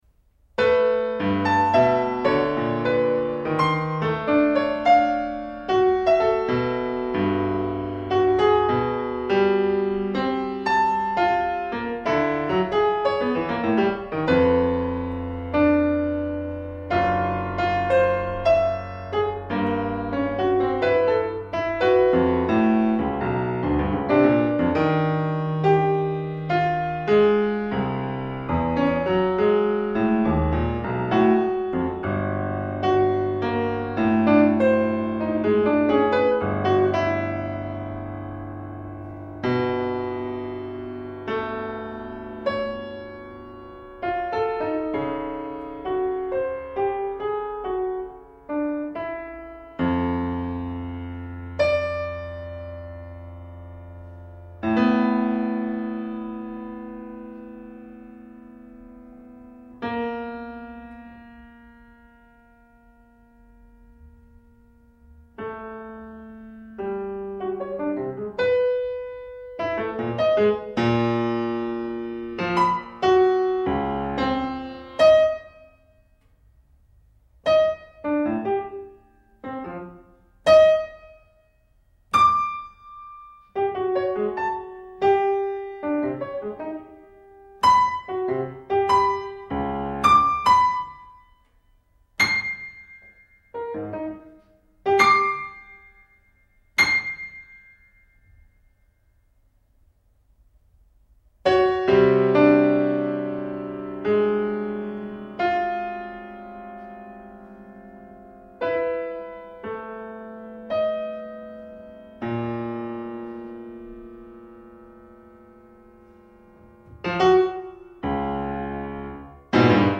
for piano, 4'